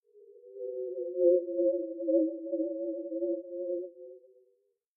WindHowl.mp3